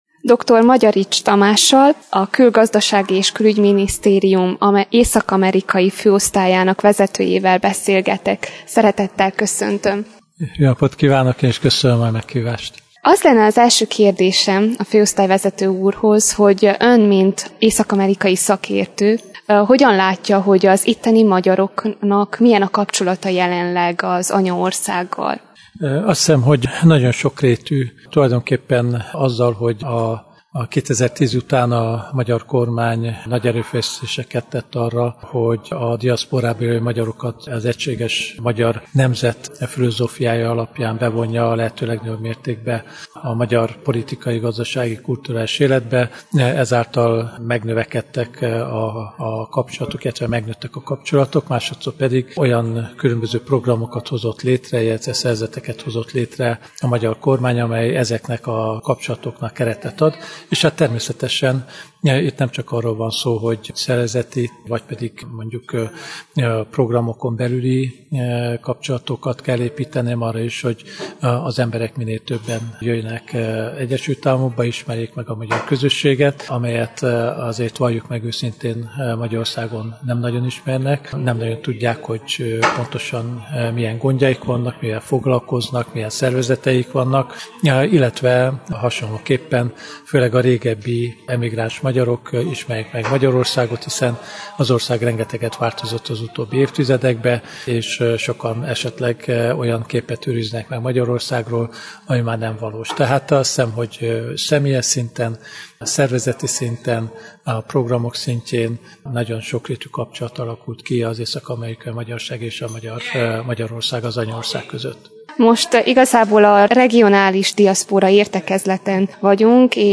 Interjú Magyarics Tamással, amerikai-magyar szakértővel – Bocskai Rádió